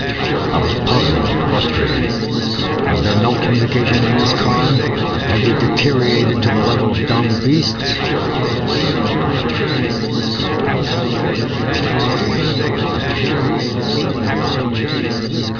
In the first, short speech recordings are obscured by a noise which is meant to resemble that in a crowded room with several people talking at the same time.
• In all examples, the speech consists of (slightly slurred) American English, spoken by a man
• All sound files are in the  .wav format (mono)
Part 1: Obscured speech
This last one is a bit harder, so the speech levels are higher than in the two previous examples.